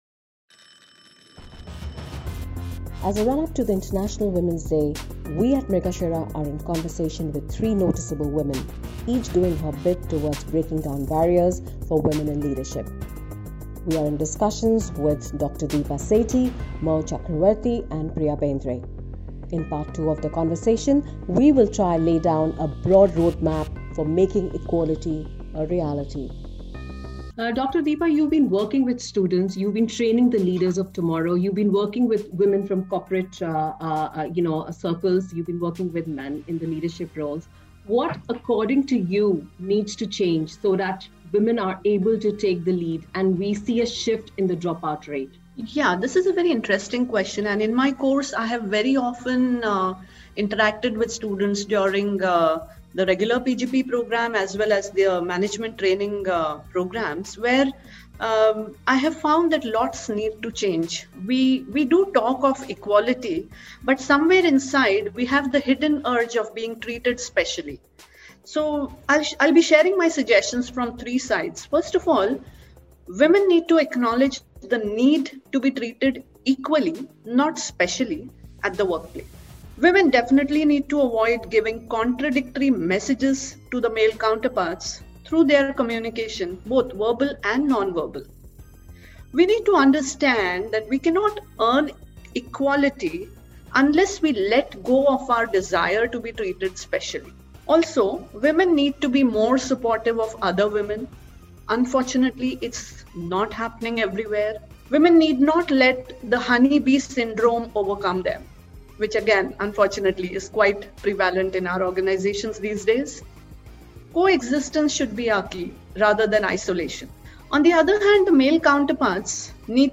Members of WICCI PR and Digital Marketing Council Discuss ‘Women and likeability’ on International Women’s Day
Panel Discussion